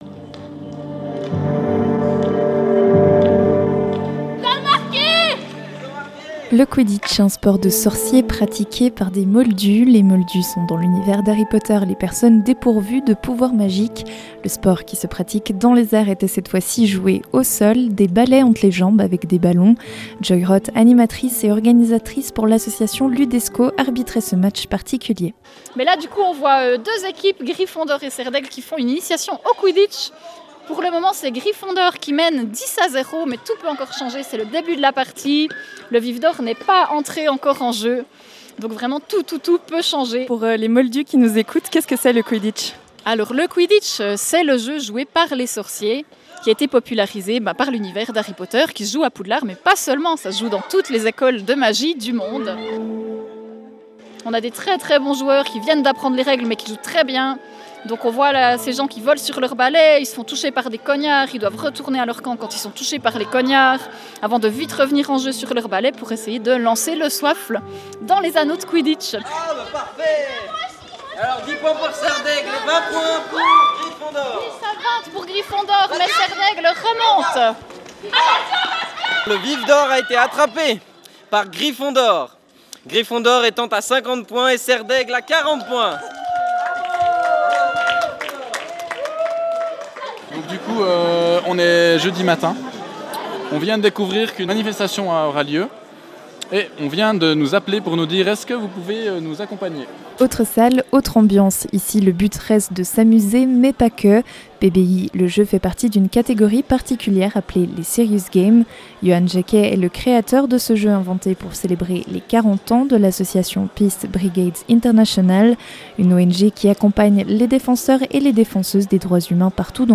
podcast sur le reportage réalisé lors de la journée de dimanche.